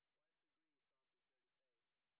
sp05_white_snr10.wav